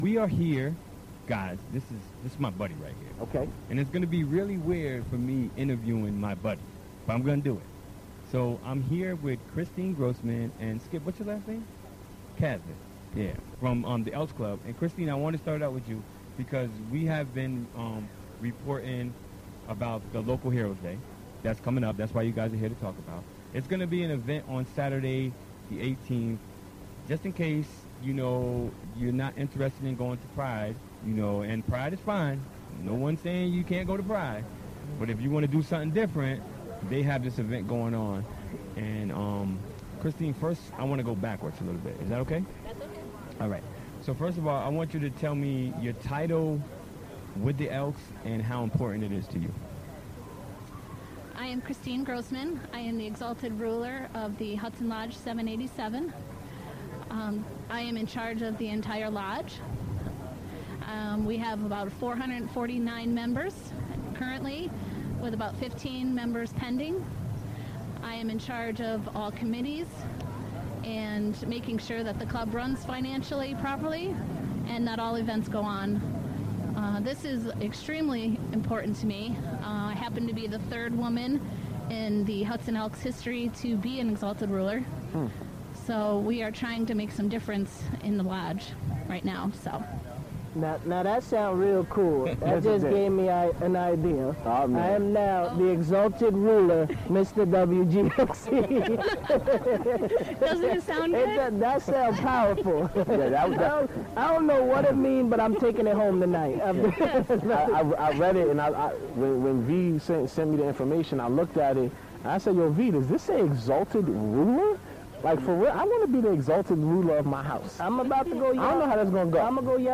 Live Remote Broadcast: Jun 15, 2016: 4pm - 6pm
Recorded on June 15, 2016 during the WGXC Afternoon Show "All Together Now!" Pledge Drive remote broadcast at the Upstreet Farmers Market in 7th Avenue Park in Hudson.